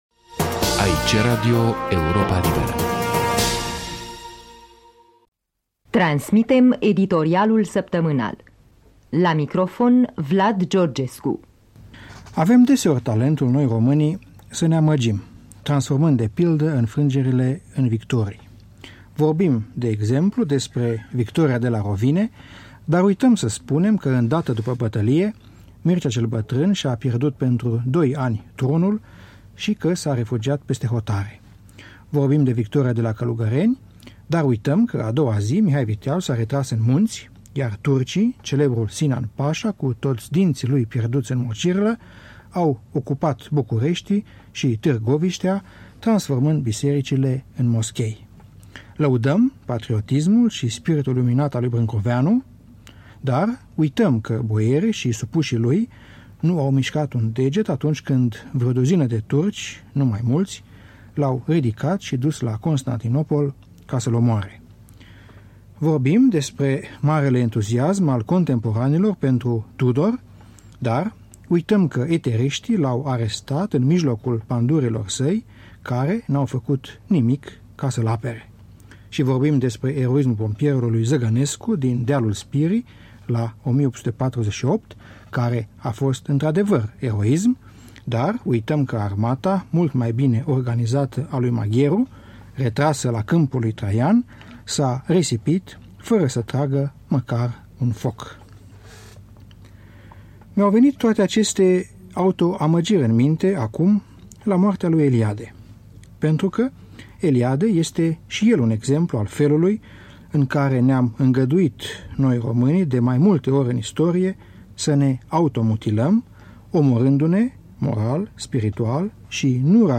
Un editorial săptămânal